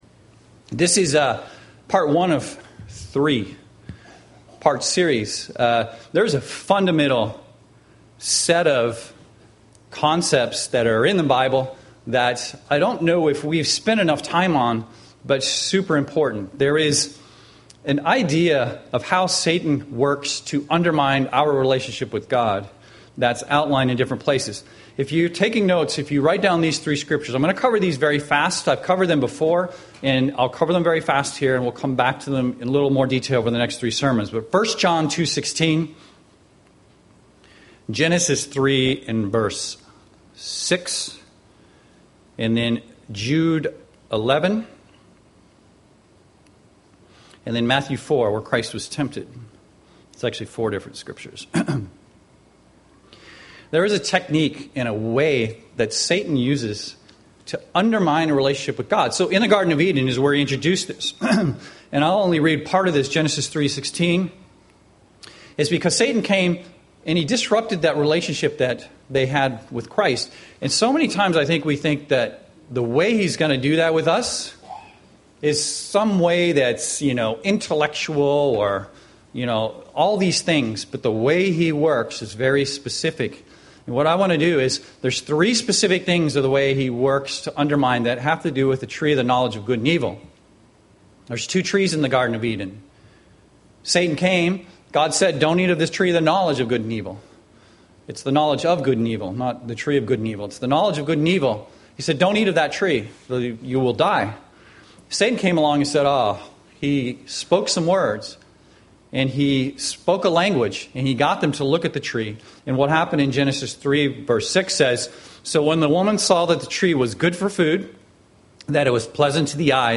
Given in Seattle, WA
UCG Sermon sin pride Studying the bible?